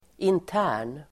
Uttal: [int'ä:r_n]